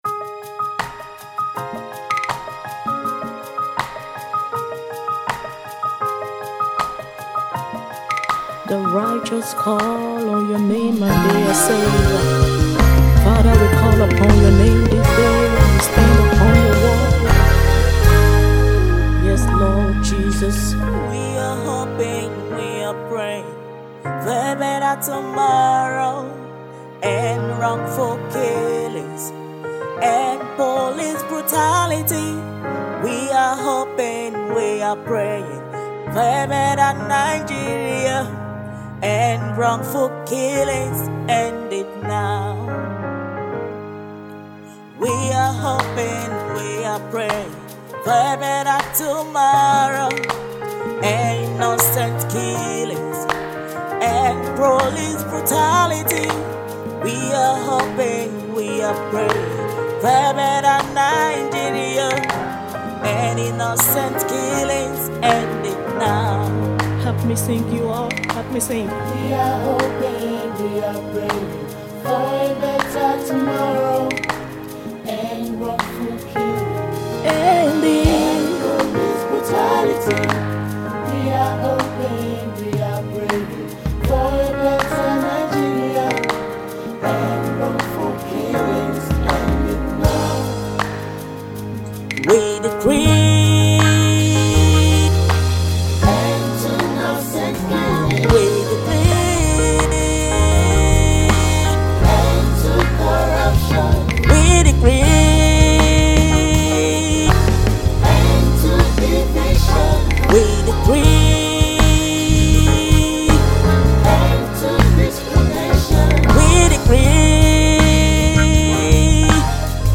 Nigerian Gospel music
Exquisite gospel songstress and songwriter